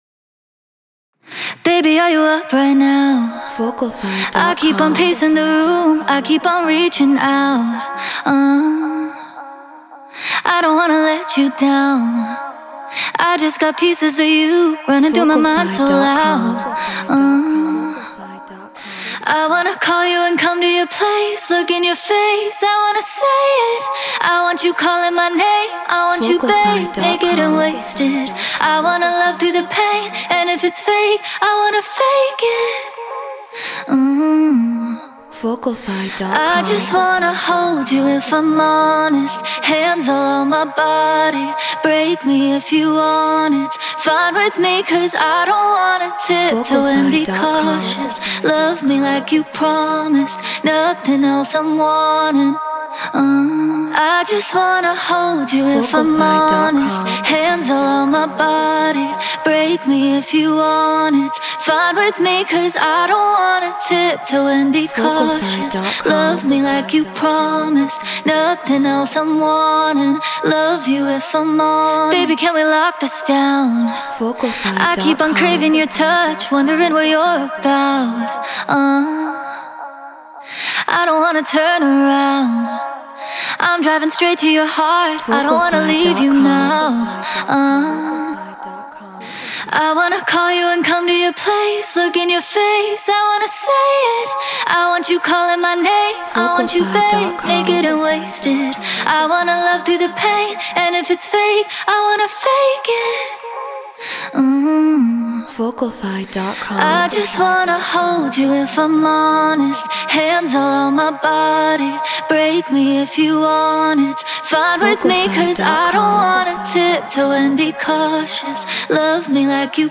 RnB 109 BPM A#min